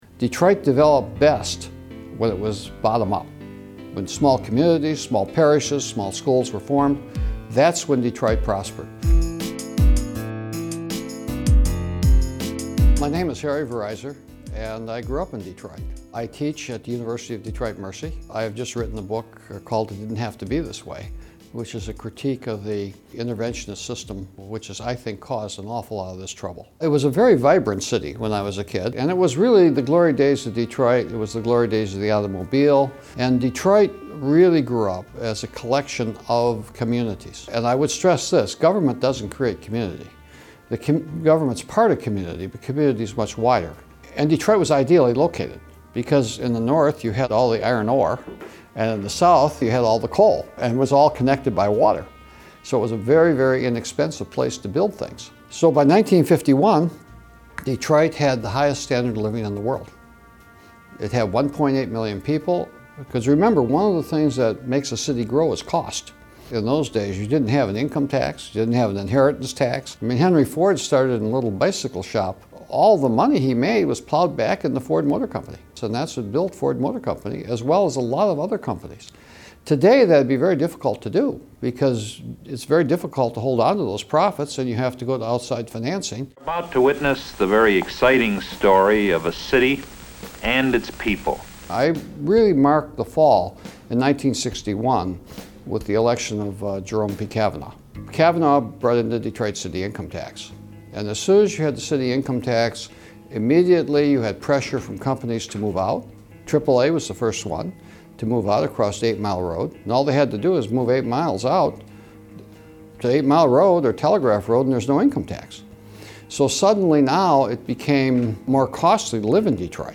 sat down with Reason TV to talk about his experience growing up in Detroit, what went wrong, and how to fix it.